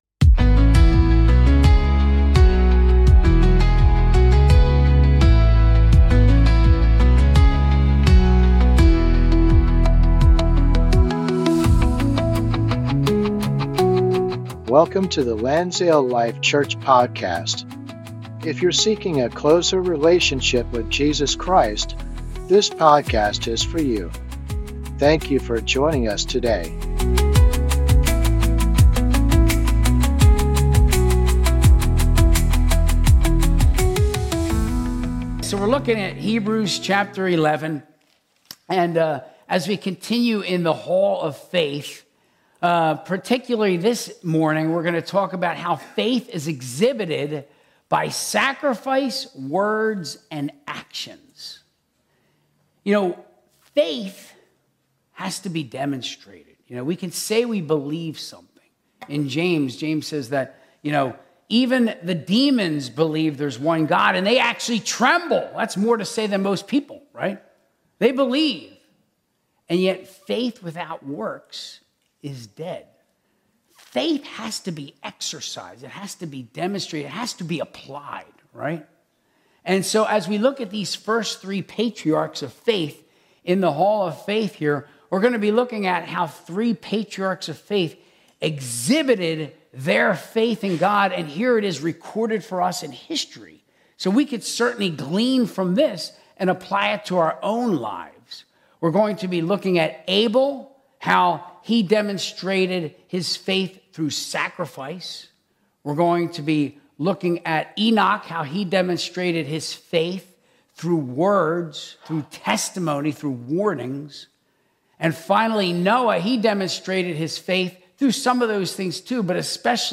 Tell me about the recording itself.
Sunday Service - 2025-11-09